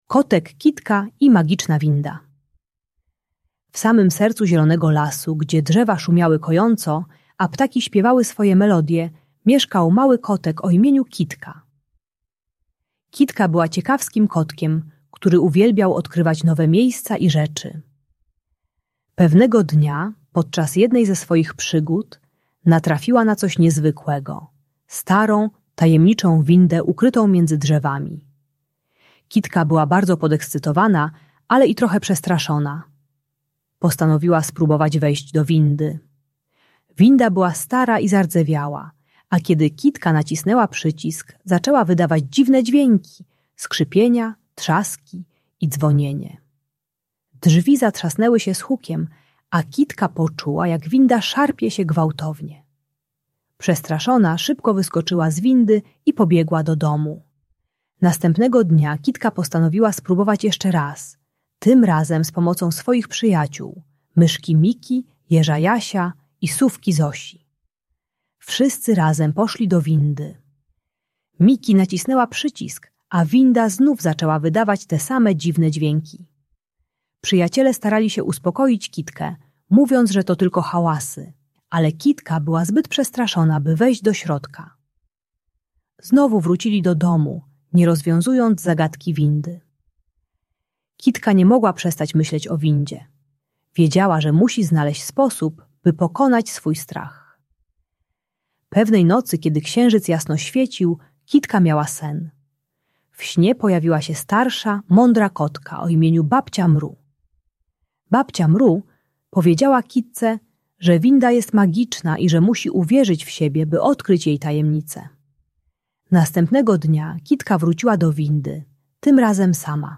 Kotek Kitka i Magiczna Winda - Lęk wycofanie | Audiobajka